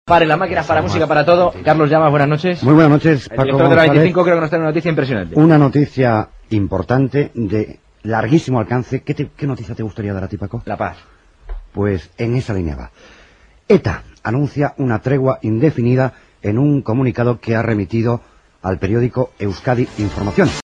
Interrupció del programa per donar la notícia que la banda ETA ha anunciat una treva
Esportiu